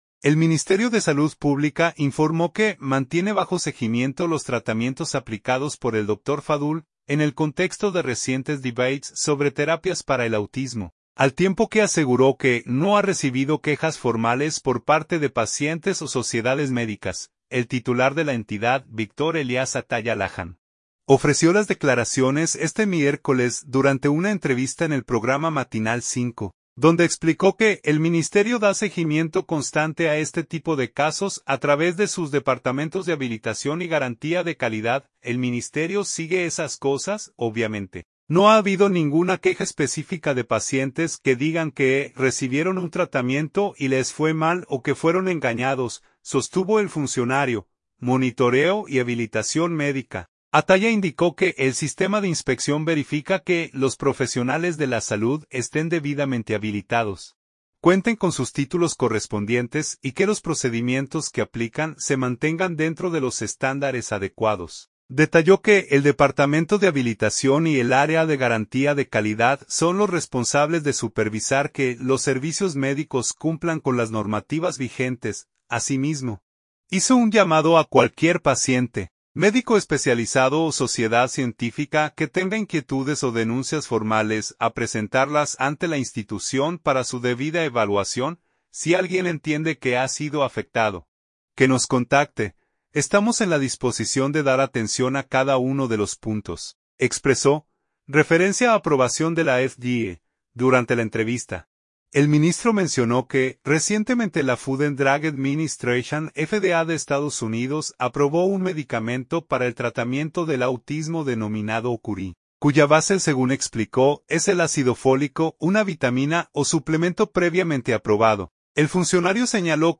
El titular de la entidad, Victor Elias Atallah Lajam, ofreció las declaraciones este miércoles durante una entrevista en el programa Matinal 5, donde explicó que el Ministerio da seguimiento constante a este tipo de casos a través de sus departamentos de habilitación y garantía de calidad.